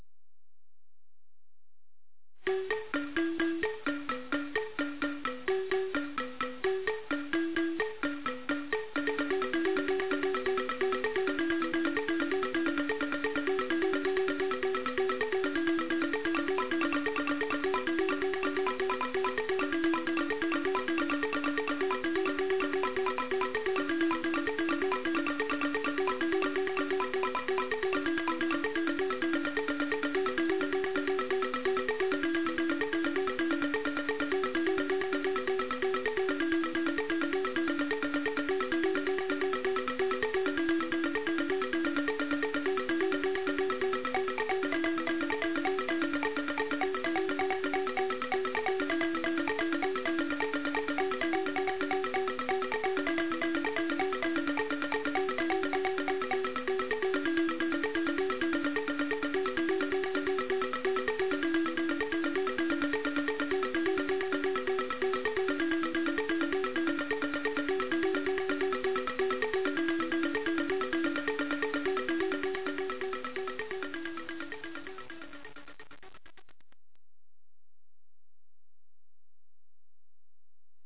AMADINDA - das Xylophon aus Buganda
Die folgenden Klangbeispiele (Computersimulationen) zeigen am Lied Ssematimba ne Kikwabanga (Ssematimba und Kikwabanga), wodurch die Wahrnehmung der zweitönigen (und der dreitönigen) Melodie unterstützt oder gestört werden kann. In KB 17 werden die beiden Melodien nacheinander in den Vordergrund gerückt.